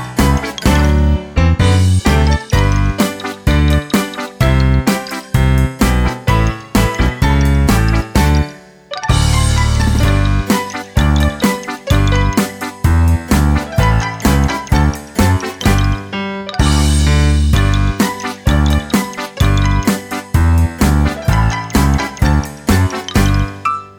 No Backing Vocals Comedy/Novelty 2:11 Buy £1.50